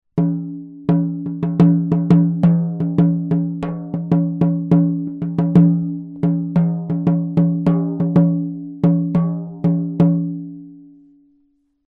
Goatskin & Wood, Bali
Good quality light shaman´s frame drum with a rich sound. Wooden frame covered with goatskin - made in Bali.
Sound sample shaman drum 40cm
schamanen-trommel-ziegenhaut-40cm.mp3